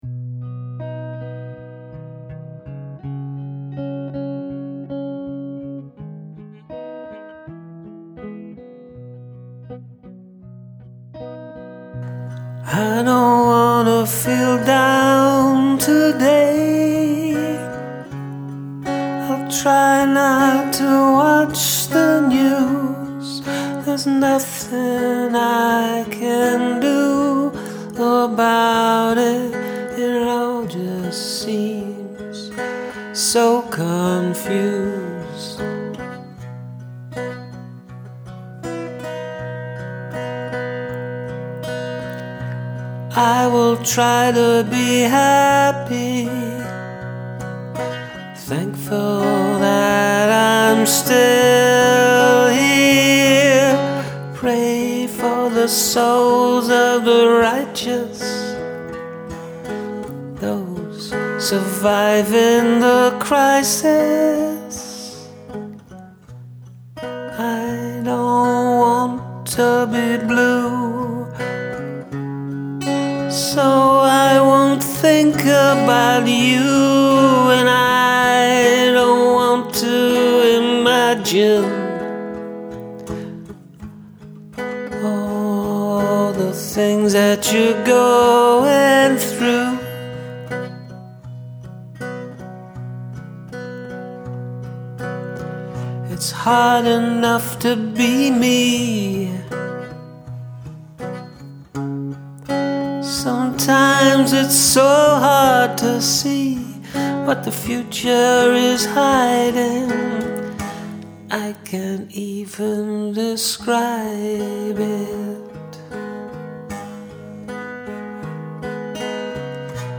This is really pretty and poignant.